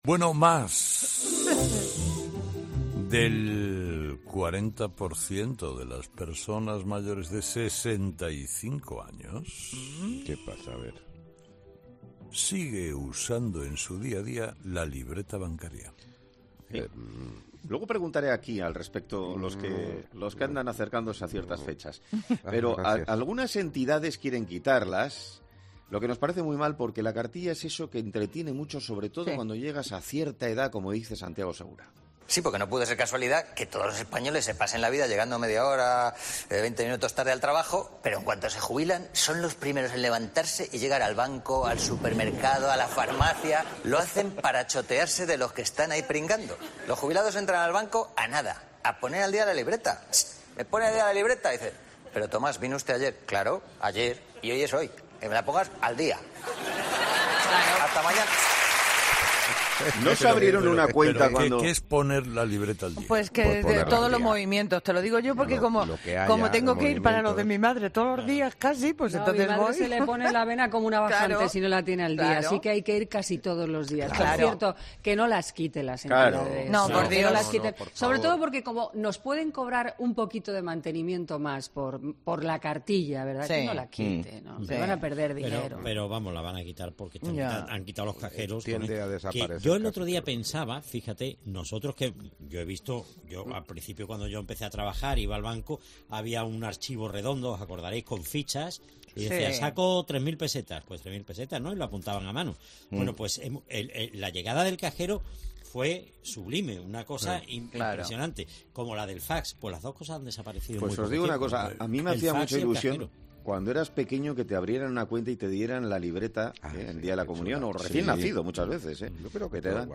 En este punto, Carlos Herrera reflexionaba sobre en qué consiste "poner la libreta al día", generando multitud de comentarios por parte de sus colaboradores.